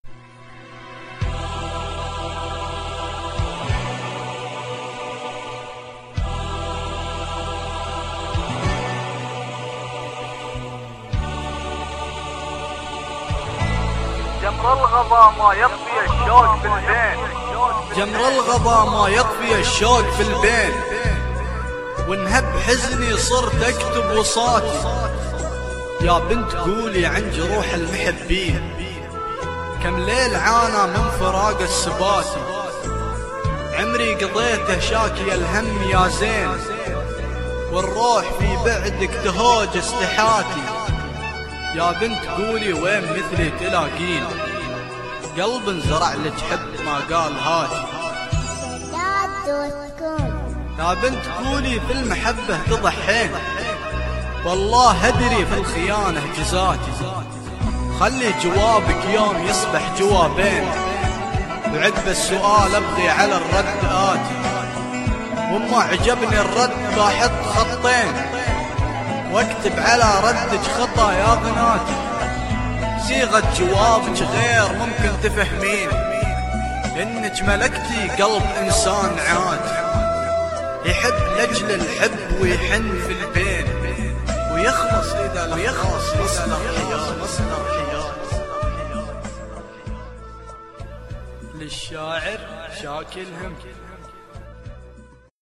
|--*¨®¨*--| القصيدهـ الصوتيهـ |--*¨®¨*--|